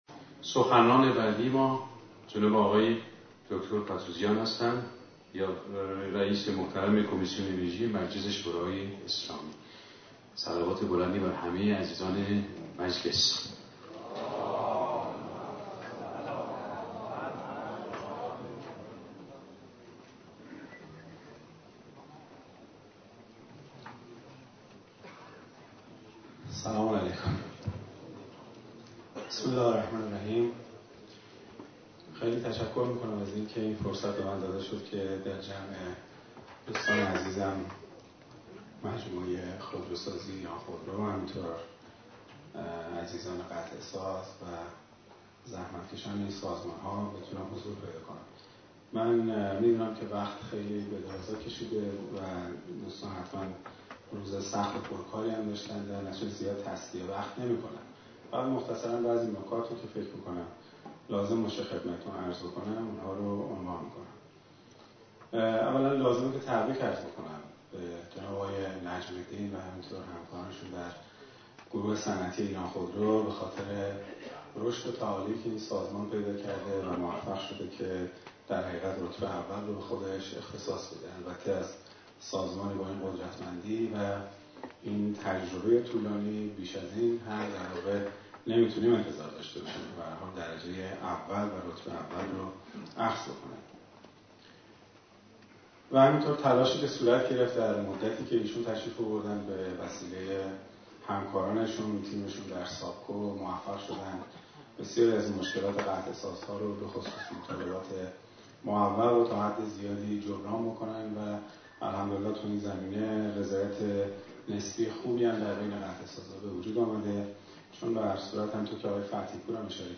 در شرایط فعلی که صنعت خودروسازی کشور نیازمند نقد منصفانه و سازنده به منظور بهبود روند فعالیت ها و کسب رضایت بیشتر مردم عزیز کشورمان است، پرشین خودرو تصمیم گرفت تا با رو کردن سند صوتی آقای "ک"، که در مدح و ثنای یکی از مدیران دو خودروسازی بزرگ کشور سخنرانی کرده است گامی کوچک به منظور شفاف سازی فضای رسانه ای کشور بردارد.